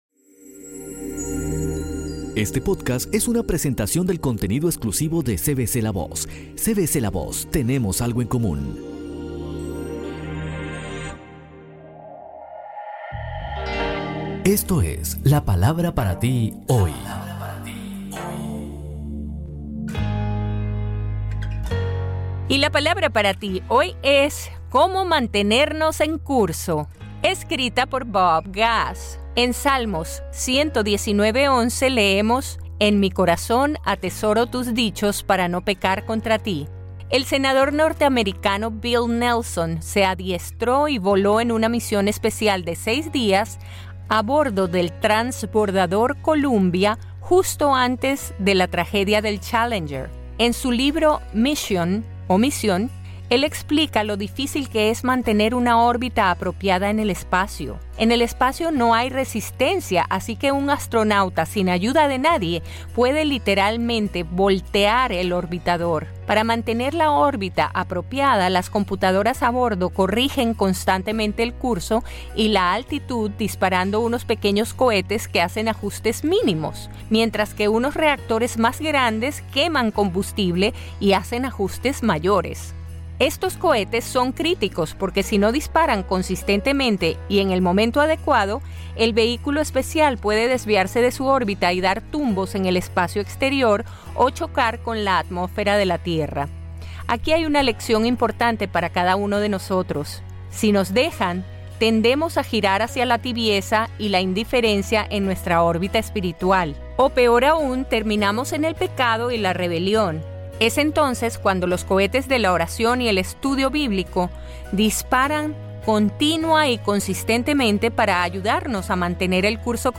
Otro episodio de los devocionales escritos por Bob Gass y narrados por Elluz Peraza. En este caso nos indica cómo mantenernos en los caminos de Dios y no invita a leer la Biblia para ver en lo que se basa este podcast.